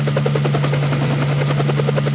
1 channel
wraithrotors.wav